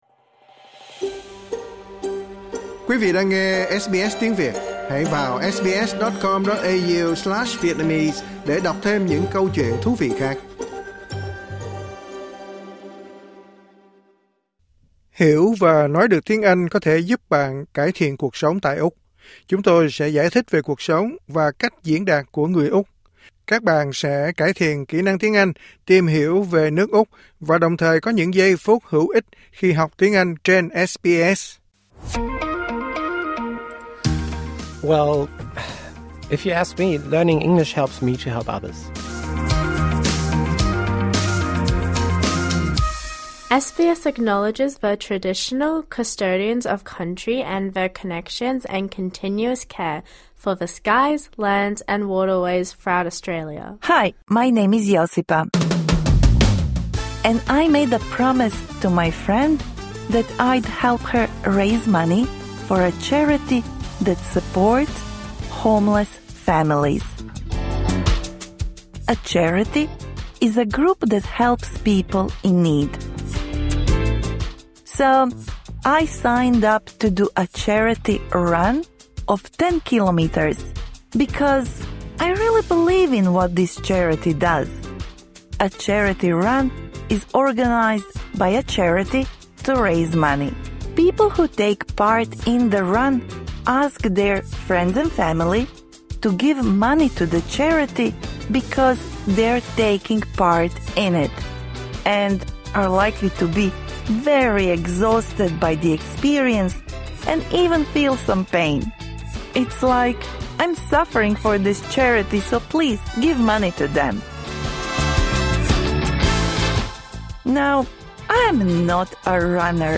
Học cách lạc quyên bằng tiếng Anh. Bài học này phù hợp với người học trình độ trung cấp.